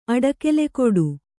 ♪ aḍakelekoḍu